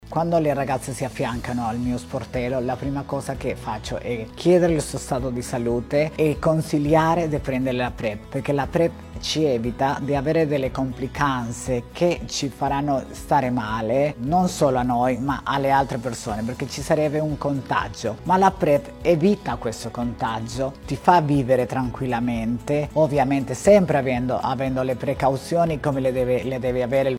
E’ la campagna promossa da Anlaids, Arcigay, Cnca, Croce Rossa e altre organizzazioni per l’utilizzo della PrEP, la profilassi Pre-Esposizione che riduce il rischio di contrarre l’HIV di oltre il 99%. Ascoltiamo la testimonianza
operatrice sociale.